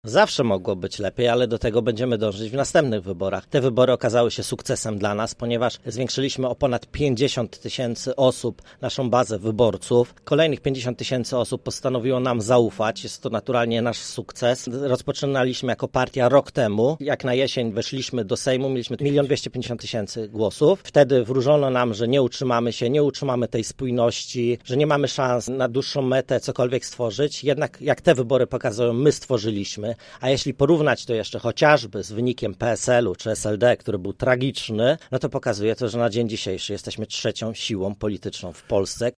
Krystian Kamiński podsumował wynik głosowania w Rozmowie Punkt 9: